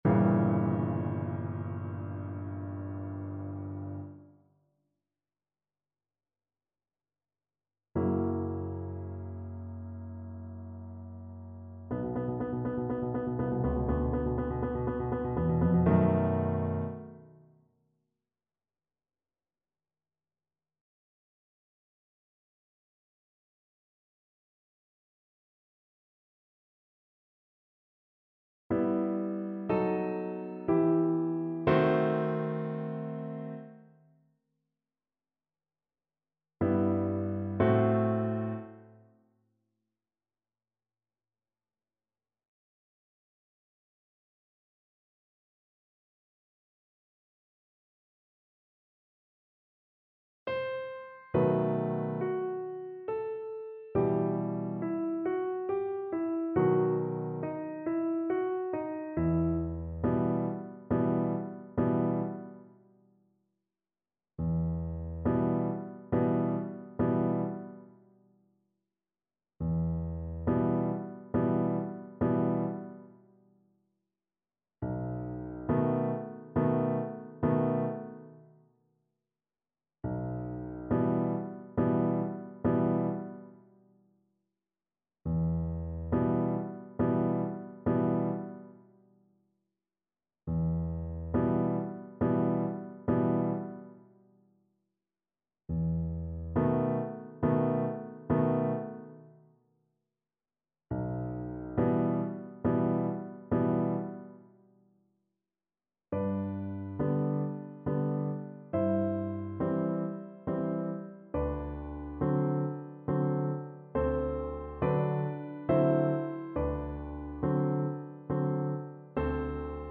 Andantino =88 (View more music marked Andantino)
4/4 (View more 4/4 Music)
Classical (View more Classical Voice Music)